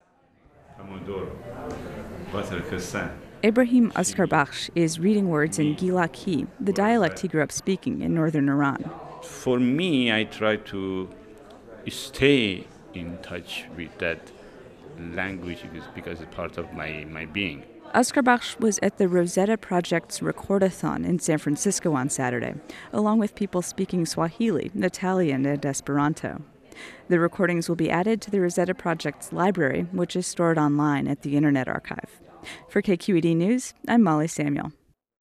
This aired on KQED News.